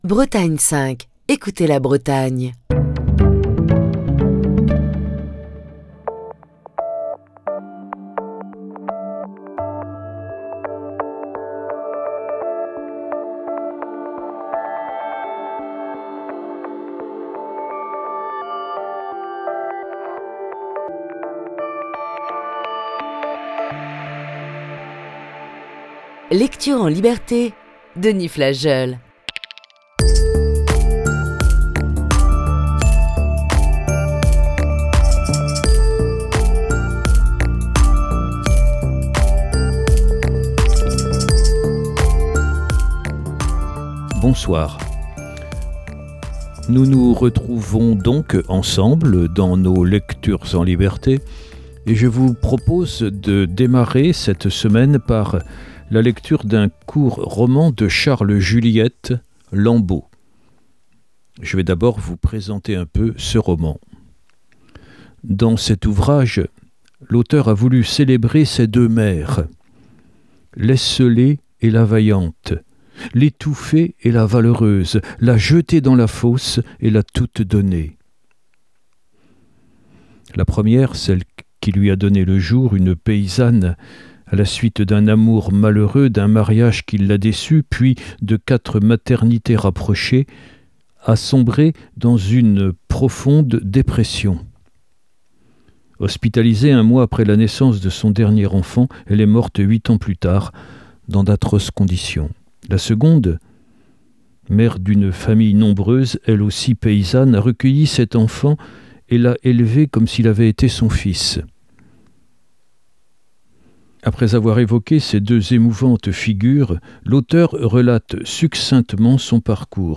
Voici ce soir la première partie de ce récit.